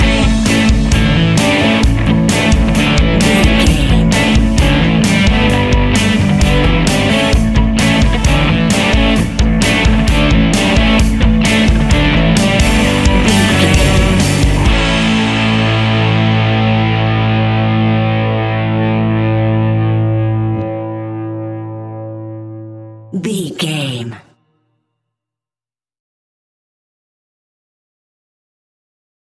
Ionian/Major
energetic
driving
heavy
aggressive
electric guitar
bass guitar
drums
hard rock
heavy metal
distortion
rock instrumentals
distorted guitars
hammond organ